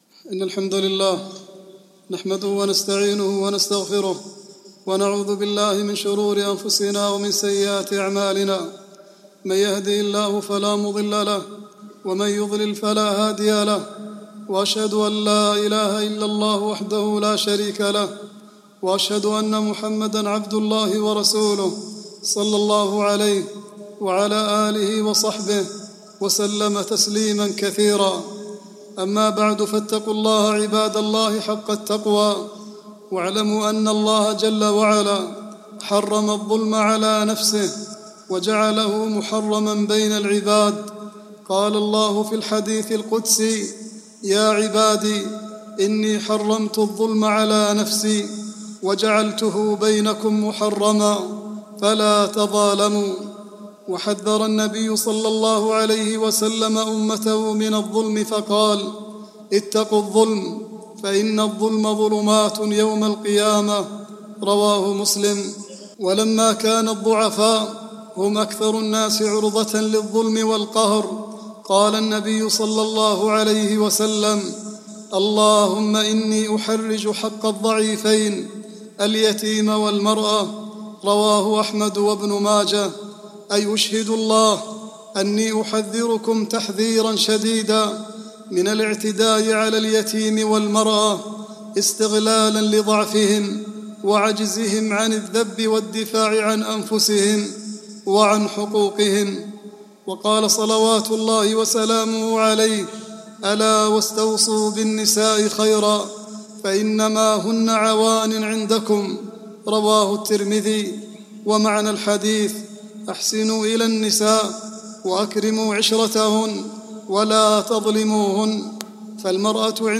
khutbah-25-6-38.mp3